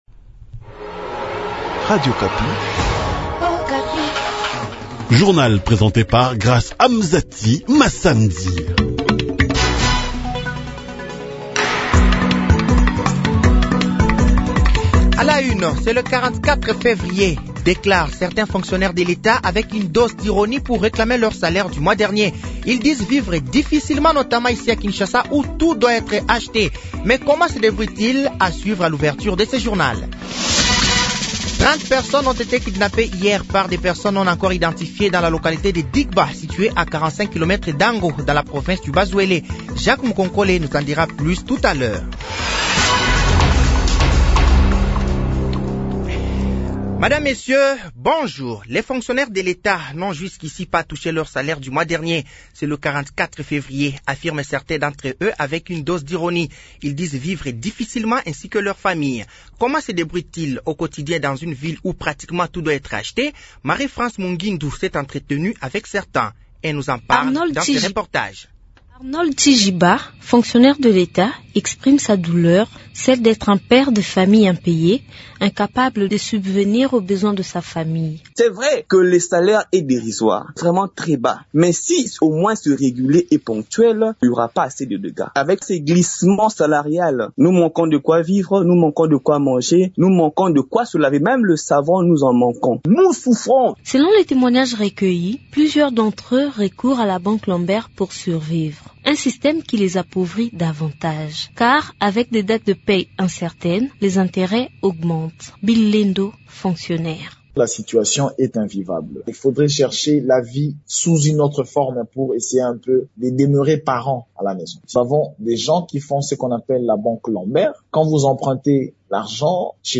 Journal français de 6h de ce vendredi 15 mars 2024